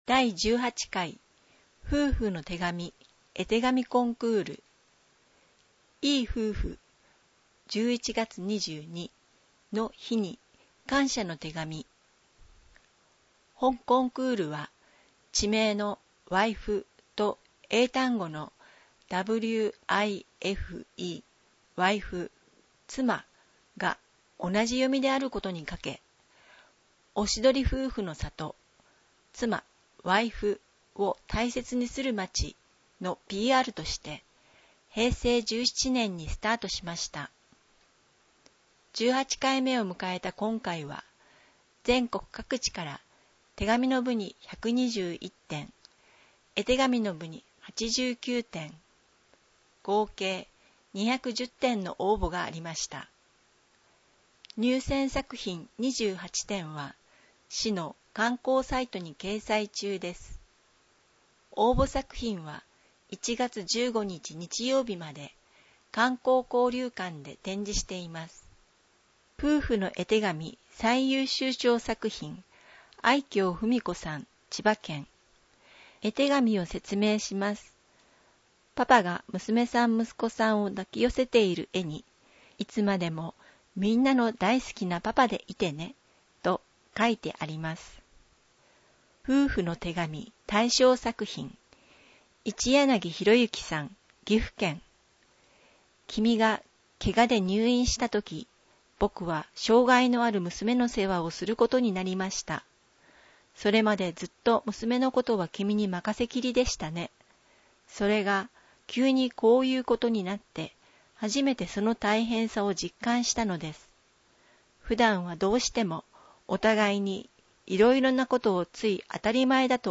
音訳